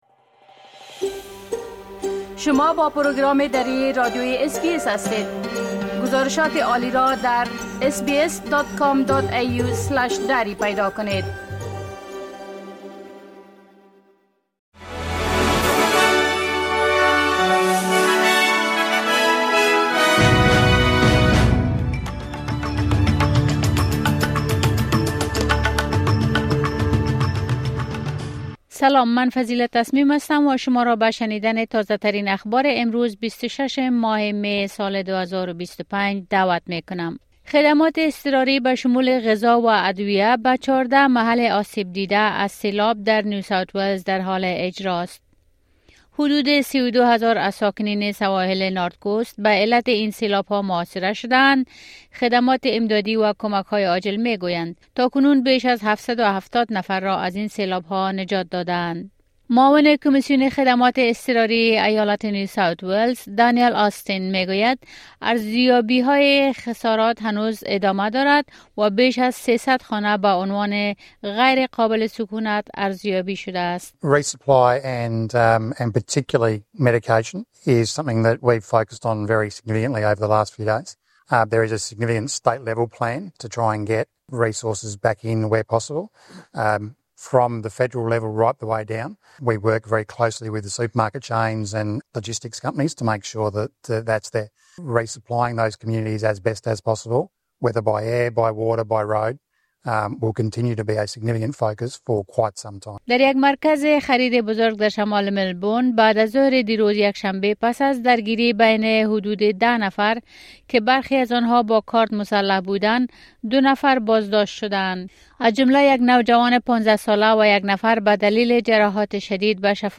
خلاصه مهمترين اخبار روز از بخش درى راديوى اس بى اس